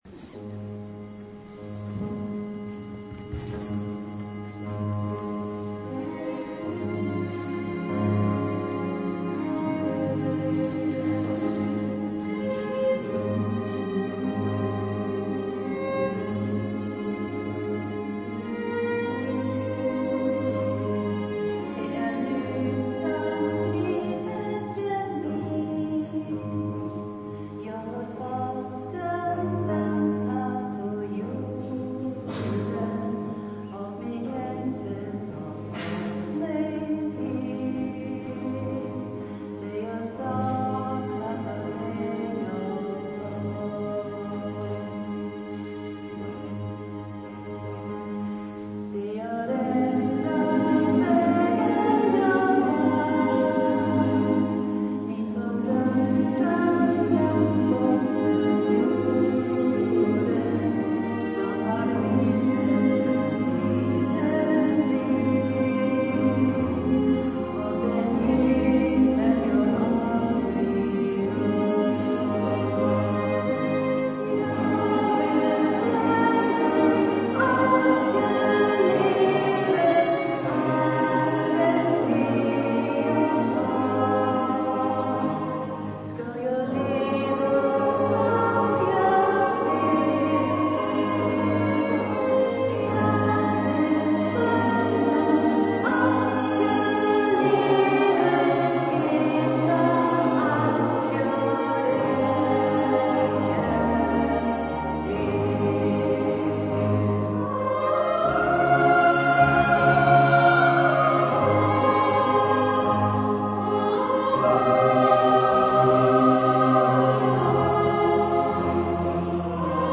Wie man auch an einem warmen Sommerabend die Kirche voll bekommt, bewies eindrucksvoll der Chor unserer Pfarre.
alle Sängerinnen und Sänger aber auch die Streicher, die Pianistin und der Drummer zur Höchstform auf.